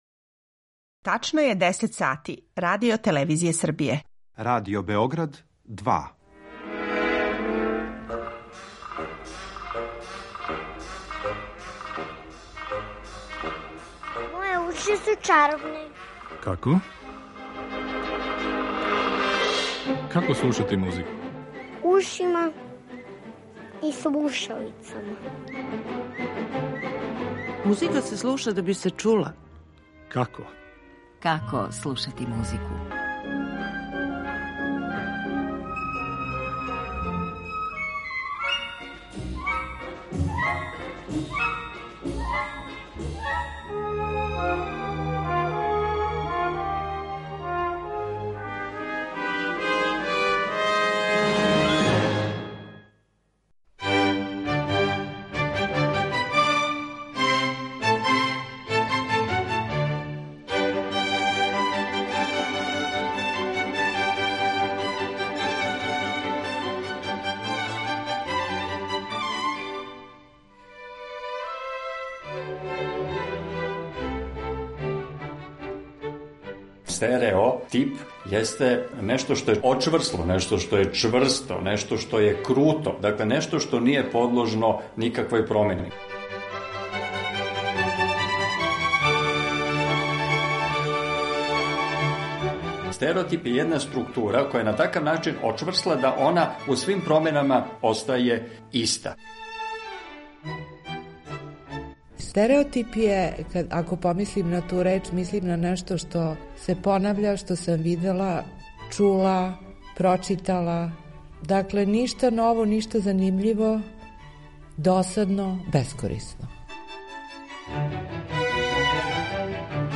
У првој емисији циклуса своје виђење овог комплексног и не баш увек јасног појма даће стручњаци из разних области науке, од филозофије - до музикологије и уметности извођаштва.